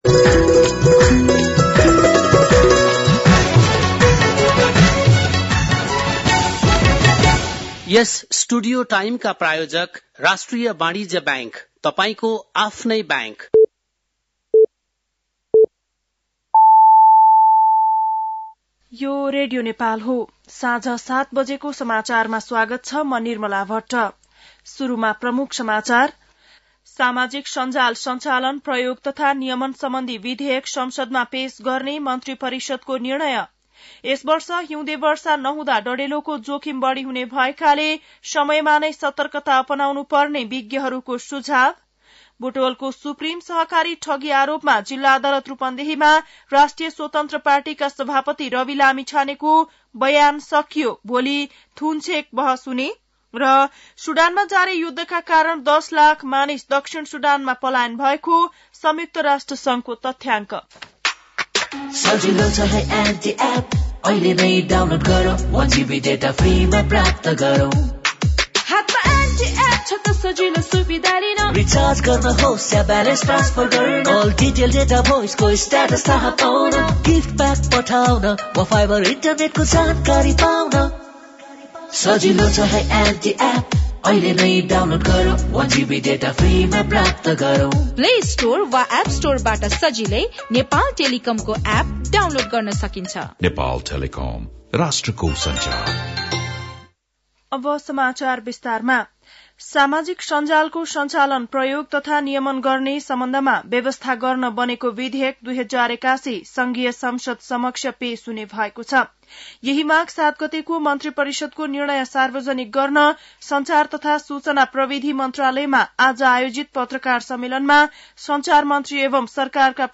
बेलुकी ७ बजेको नेपाली समाचार : १० माघ , २०८१